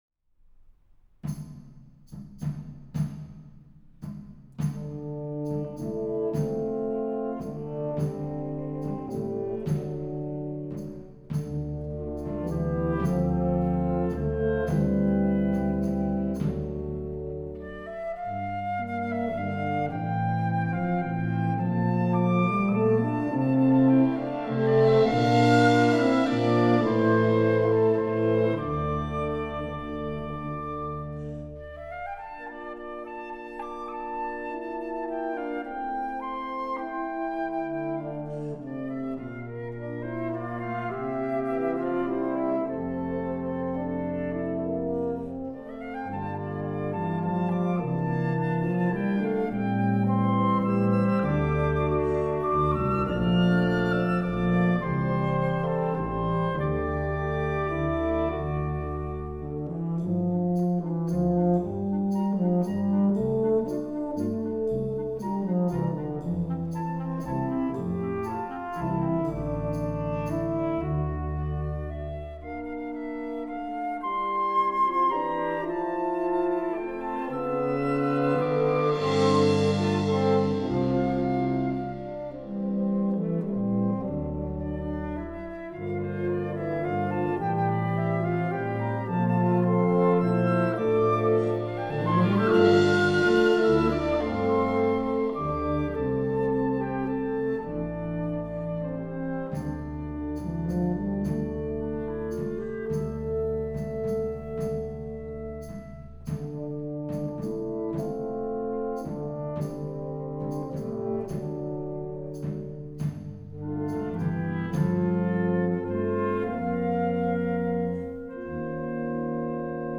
Partitions pour ensemble flexible.